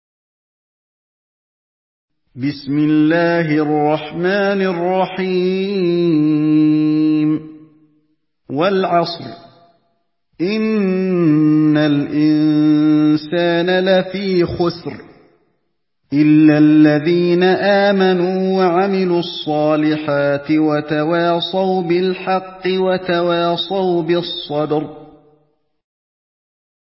Surah العصر MP3 by علي الحذيفي in حفص عن عاصم narration.
مرتل